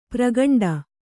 ♪ pragaṇḍa